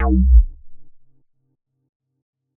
Bass Funk 3.wav